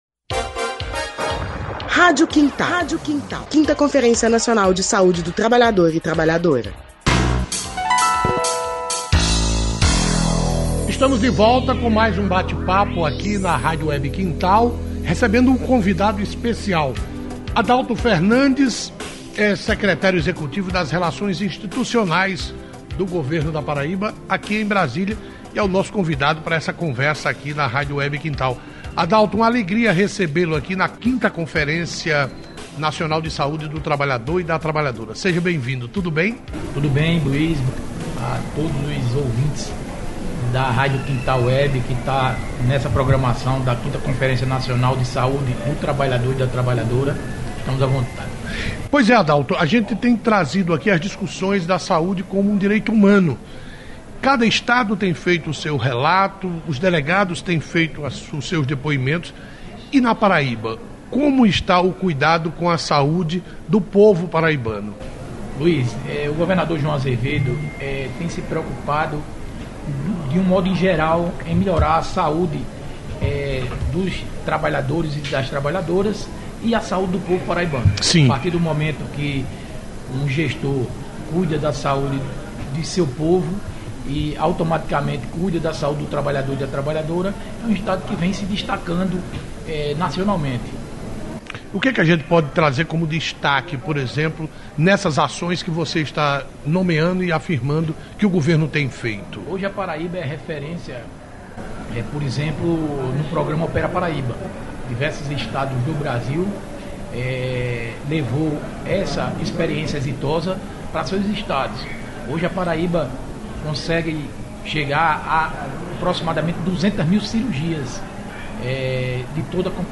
O secretário executivo de relações institucionais da Paraíba, Adauto Fernandes, participou da 5ªConferência Nacional de Saúde do Trabalhador e da Trabalhadora – em Brasília- e falou das ações do Governo do Estado para fortalecer o Sistema Único de Saúde. Um dos destaques é o fim das filas de cirurgias eletivas na Paraíba. Segundo o Secretário Adauto Fernandes, o governo conseguiu realizar mais de 200 mil cirurgias nos últimos 7 anos no estado.